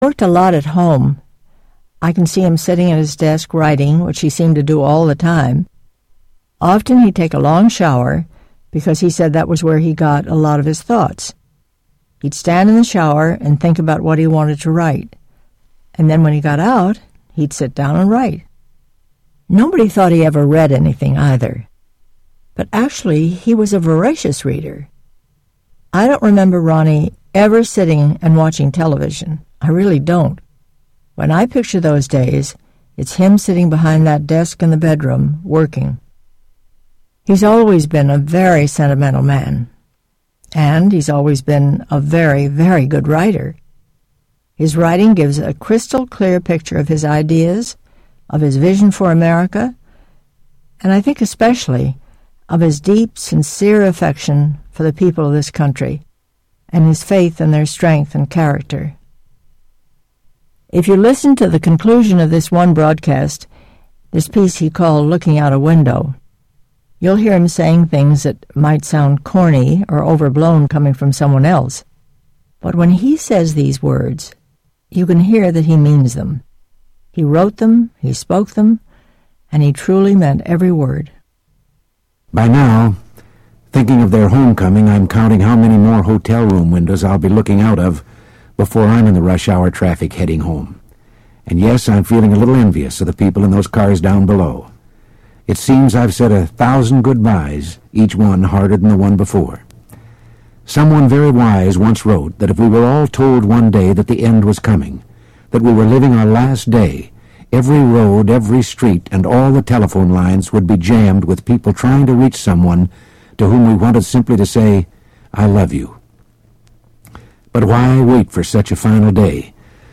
Reagan in His Own Voice 2
Tags: Autobiography Audio Clips Autobiography Autobiography sounds Autobiography Audio history